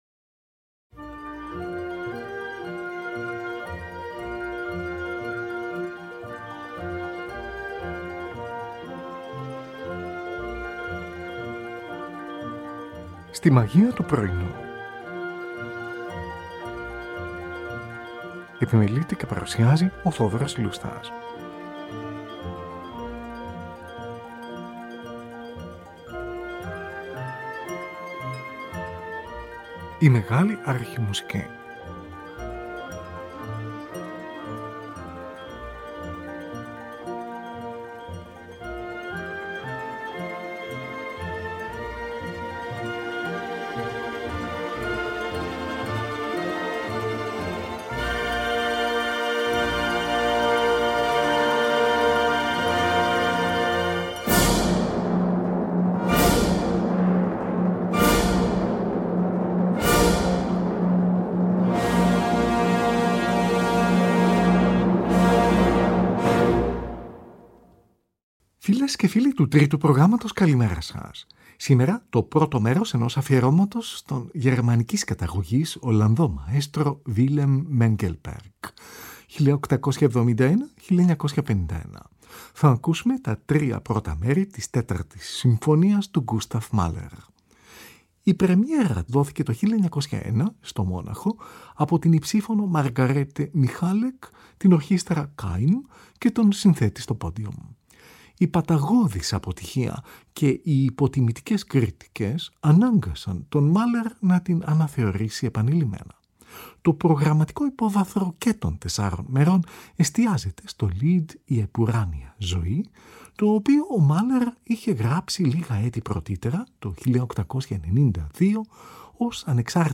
Την Ορχήστρα Concertgebouw του Άμστερνταμ διευθύνει ο Willem Mengelberg , από ζωντανή ηχογράφηση, στις 9 Νοεμβρίου 1939 .